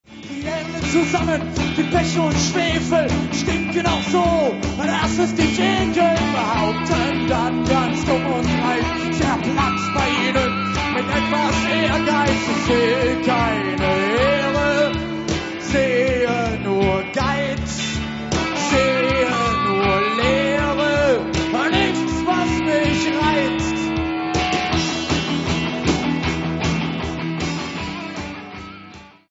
Neuen Deutschen Welle